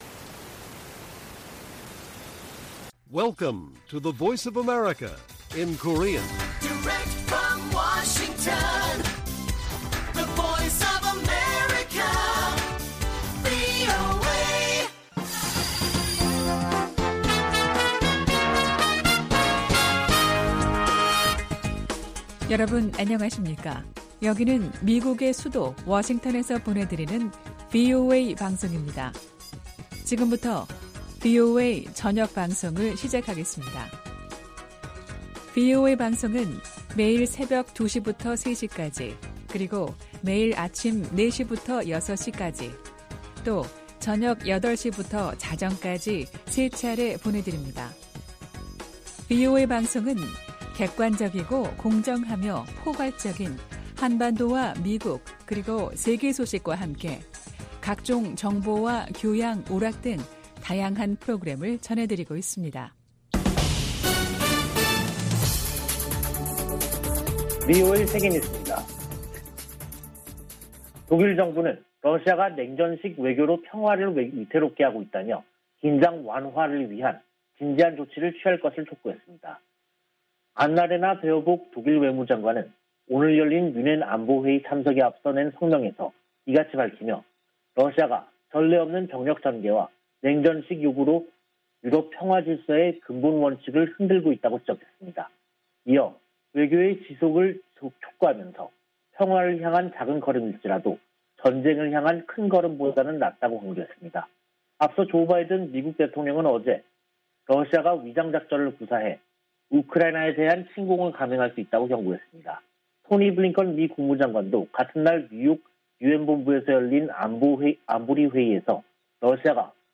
VOA 한국어 간판 뉴스 프로그램 '뉴스 투데이', 2022년 2월 18일 1부 방송입니다. 미 국무부 동아태 차관보가 미국, 한국, 일본의 최우선 과제로 북한 핵·미사일 위협 대응을 꼽았습니다. 해리 해리스 전 주한 미국대사는 대화를 위해 북한 위협 대응 능력을 희생하면 안된다고 강조했습니다. 북한이 가상화폐 자금에 고도화된 세탁 수법을 이용하고 있지만 단속이 불가능한 것은 아니라고 전문가들이 말했습니다.